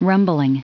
Prononciation du mot rumbling en anglais (fichier audio)